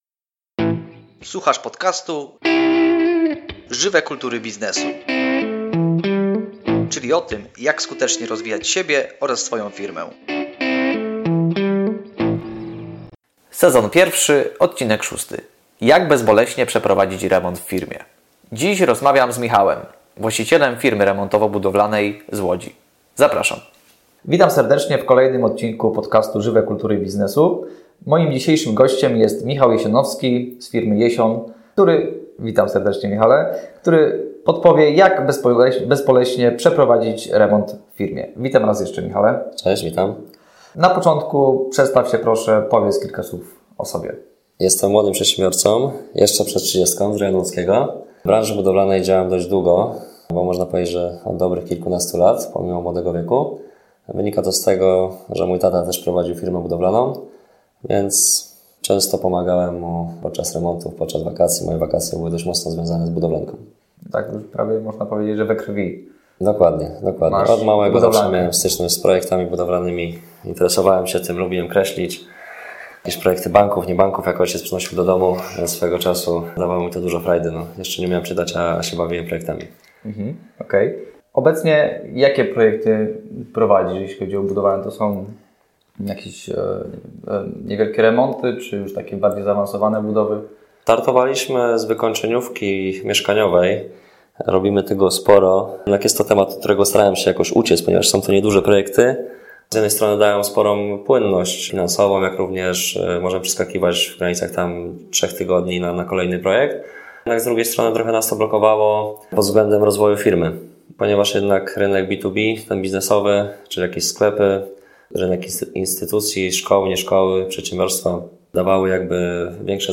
wywiad